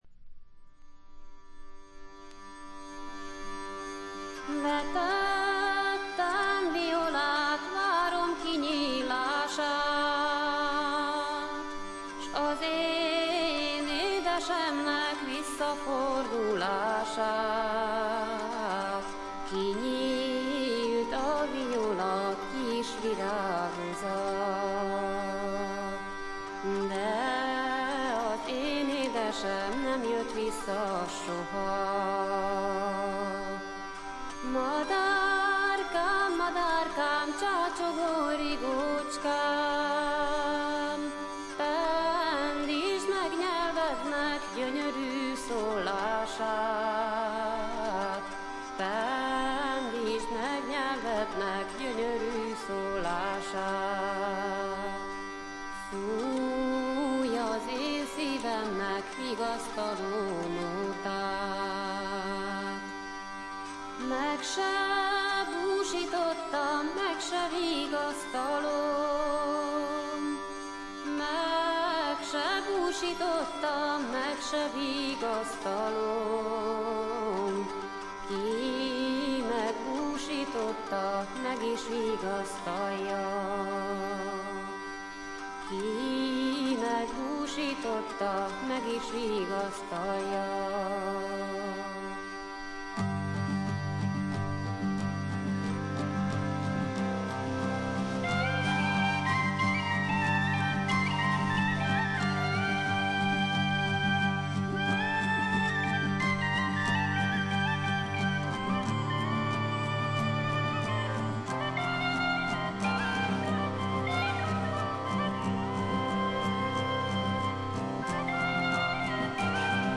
ほとんどノイズ感無し。
コンテンポラリーの分野でも活躍した彼女ですが本作ではきっちり伝統音楽を聴かせます。
試聴曲は現品からの取り込み音源です。